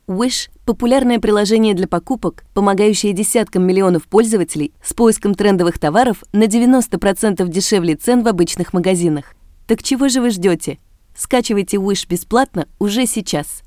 Native speaker of the standard dialect of Russian, from St. Petersburg.
Sprechprobe: Werbung (Muttersprache):